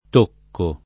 t1kko] s. m. («il toccare; l’una; effetto artistico; minima quantità»); pl. -chi — es.: un tocco leggero, energico; un tocco d’eleganza, di color locale; sentire due tocchi alla porta; dare gli ultimi tocchi a un lavoro; e con locuz. fig., a tocco e non tocco di…, «lì lì per…»: mi trovavo a tocco e non tocco di diventare un ragazzo [mi trov#vo a tt1kko e nnon t1kko di divent#re un rag#ZZo] (Collodi); e con acc. scr.: fierezza di tócchi [fLer%ZZa di t1kki] (Carducci); un tócco di maggior grazia [